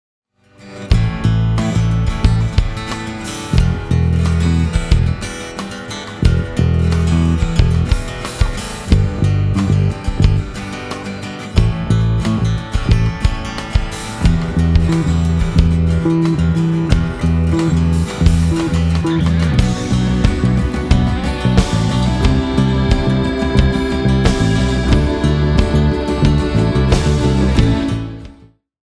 Indie-Folk-Pop-Ballade spezial ;-)
Der Output-Regler war auf 9 Uhr, also etwa 1/4 des Regelwegs.
Anhänge Excerp MT71s GAP Git.mp3 1,1 MB · Aufrufe: 473